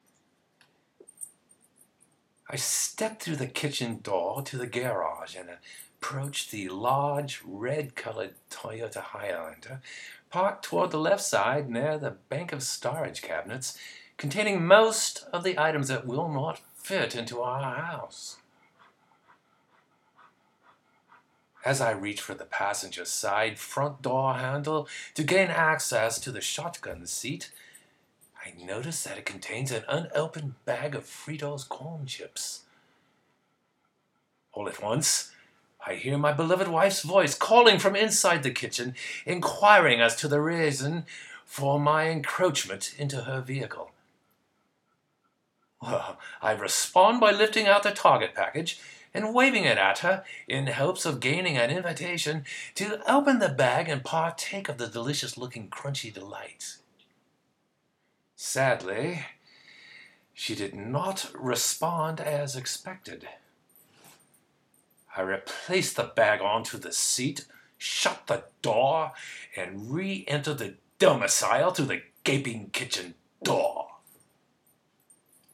In the movie, I am required to speak with a British accent.
In a British accent (sort of).
british-test-4.m4a